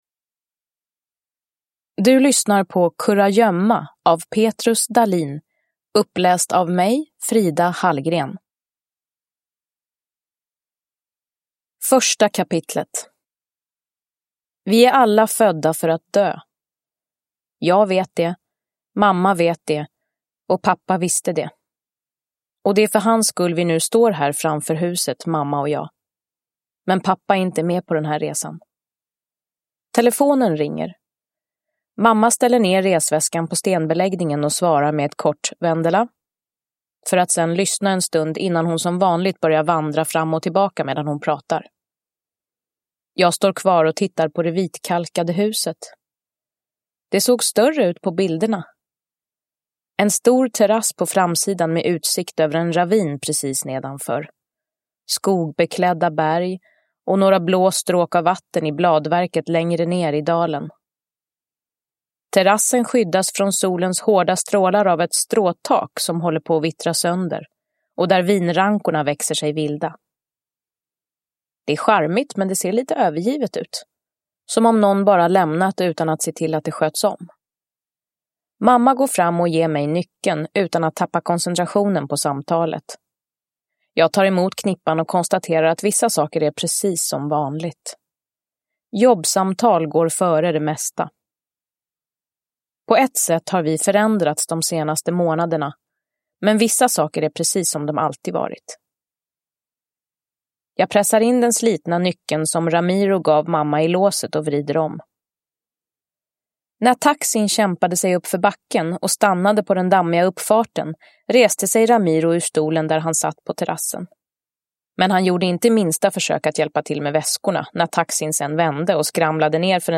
Kurragömma – Ljudbok – Laddas ner
Uppläsare: Frida Hallgren